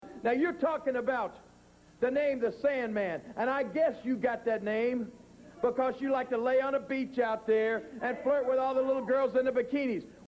jerry-lawler-sandman-promo.mp3